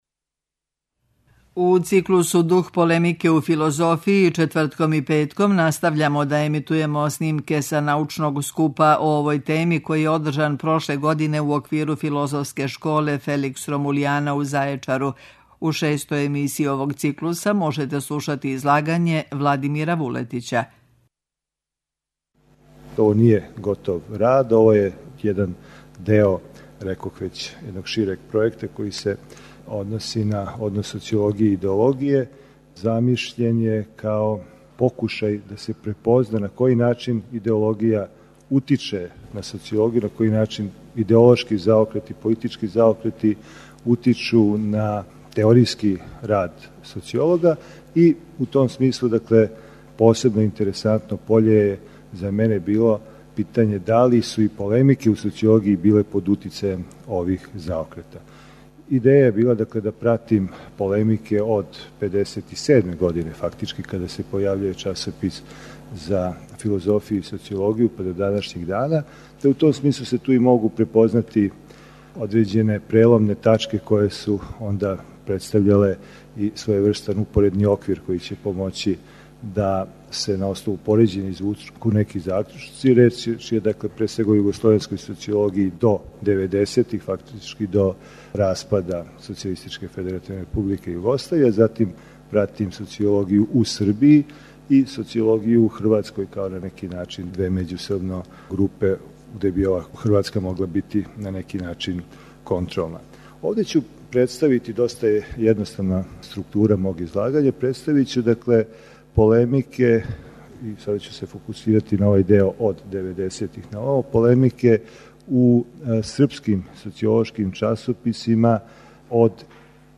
У циклусу ДУХ ПОЛЕМИКЕ У ФИЛОЗОФИЈИ четвртком и петком емитујемо снимке са научног скупа о овој теми, који је одржан прошле године у оквиру Филозофске школе Феликс Ромулиана у Зајечару.
Научни скупови